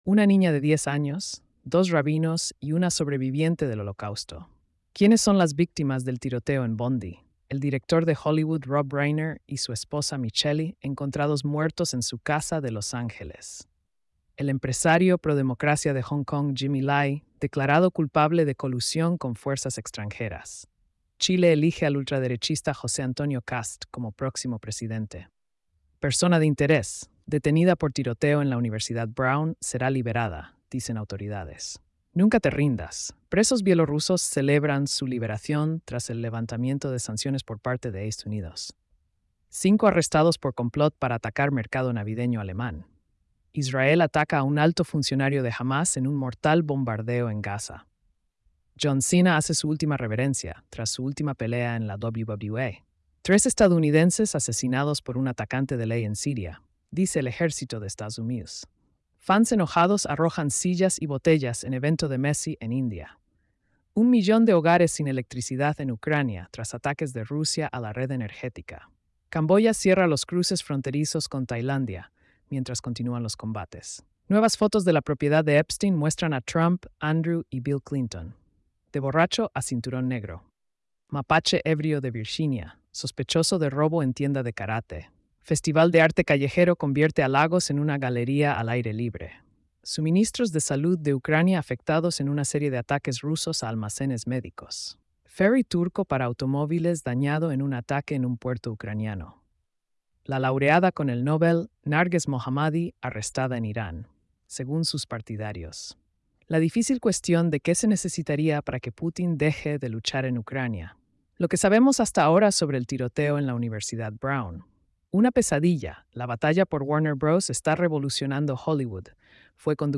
🎧 Resumen de noticias diarias. |